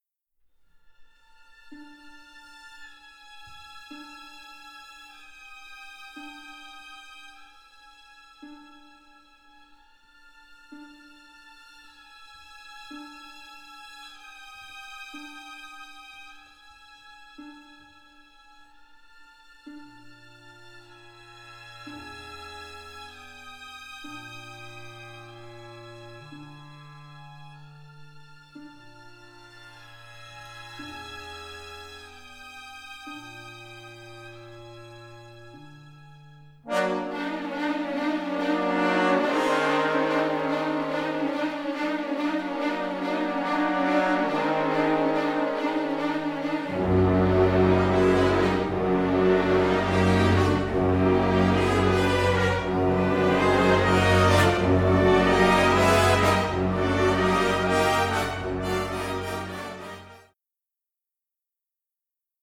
Both scores were recorded in January 2023